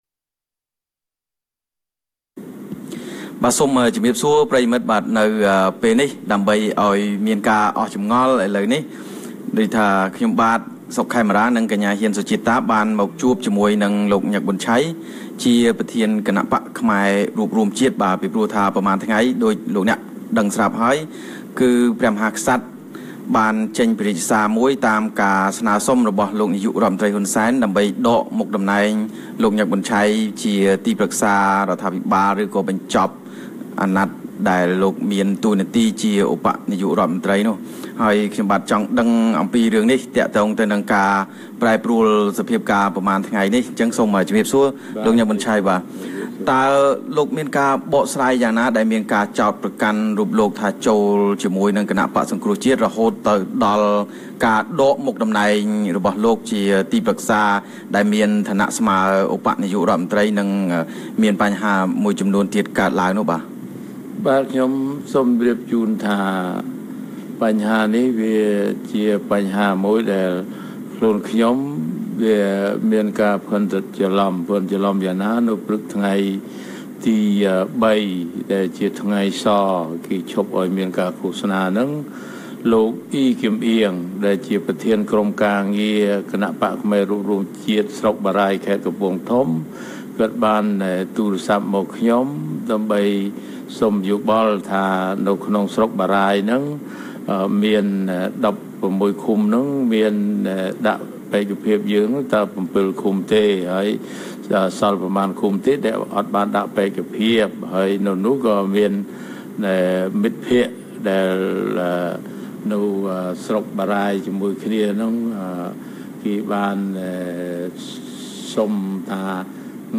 បទសម្ភាសន៍ VOA៖ លោកញឹក ប៊ុនឆៃ បកស្រាយពីការដកតំណែងដែលស្នើដោយនាយករដ្ឋមន្ត្រី